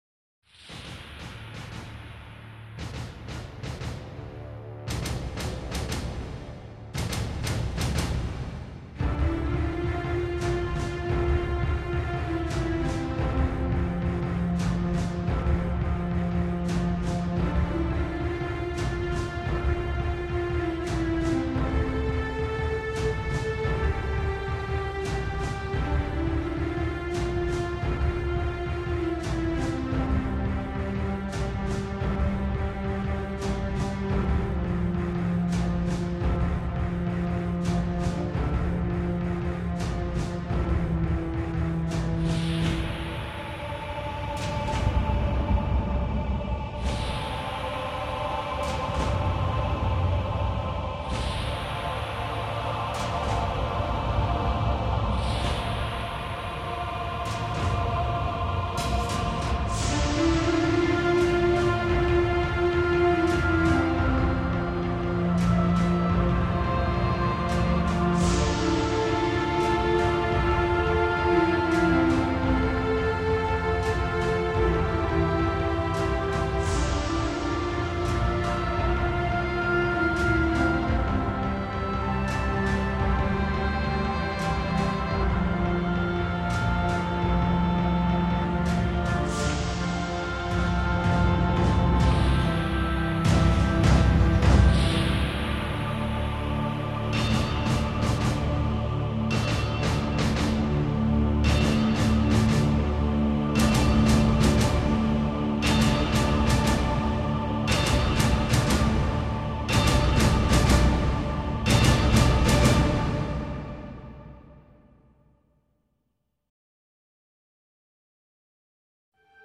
ścieżka dźwiękowa
44kHz Stereo